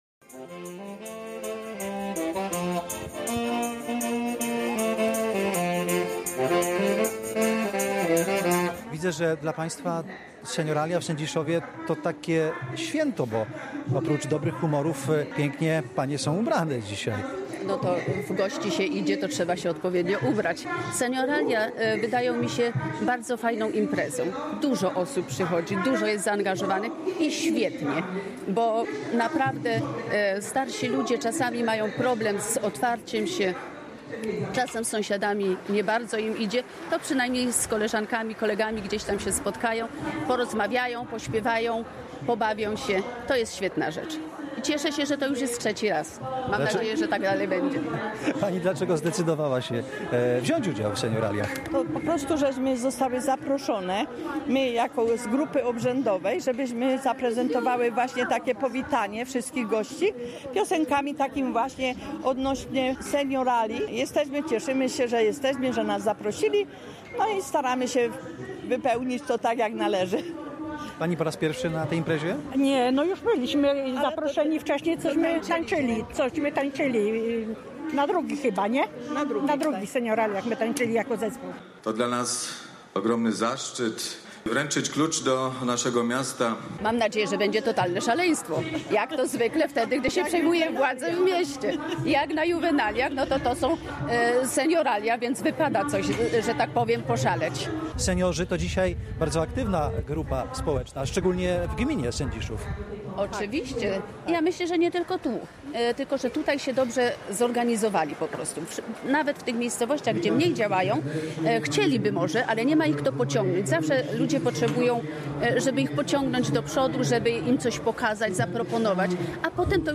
30 sierpnia Sędziszów Małopolski tętnił życiem podczas III Senioraliów. Wydarzenie to, pełne radości i wspomnień, zaczęło się od uroczystej mszy w sędziszowskiej farze. Po nabożeństwie kolorowy korowód przeszedł ulicami miasta na plac targowy w towarzystwie zespołu muzycznego.
04_09_jesien_senioralia_w_Sedziszowie_Mlp.mp3